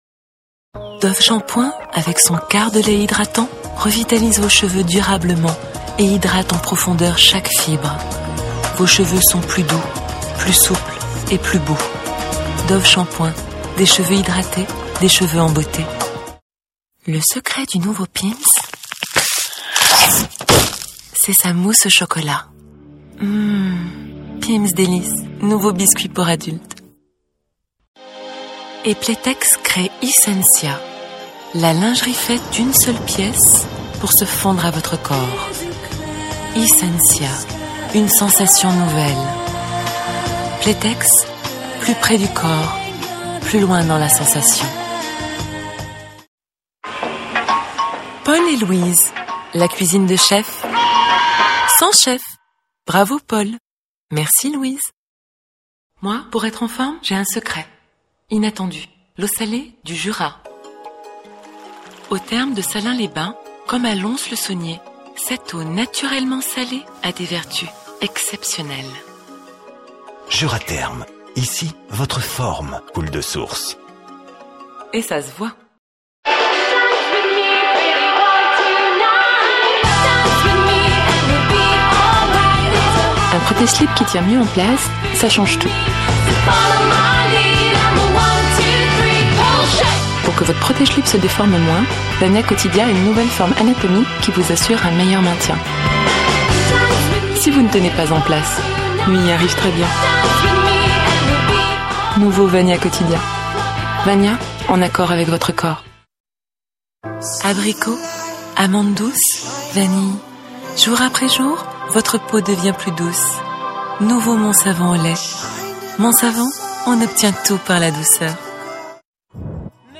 Voix off
35 - 40 ans - Mezzo-soprano